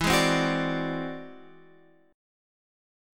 E Augmented 9th